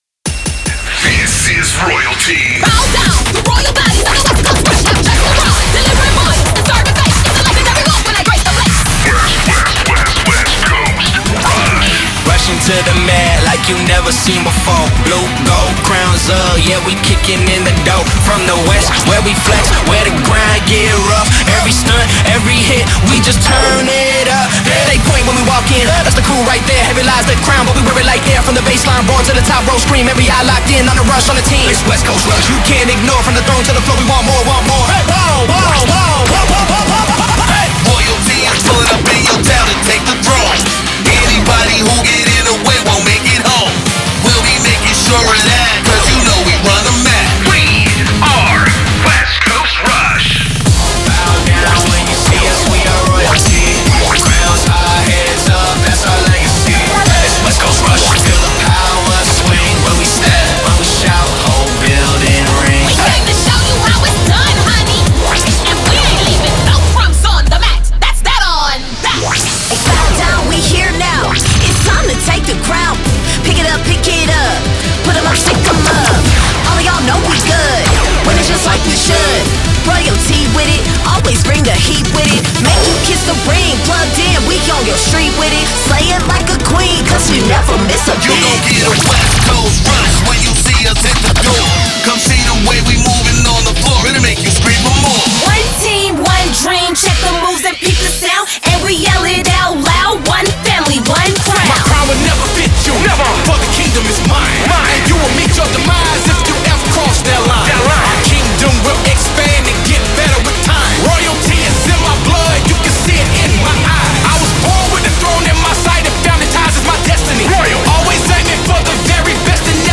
# Vocalists – 8
Includes Cover Music
Includes 8 voice over artists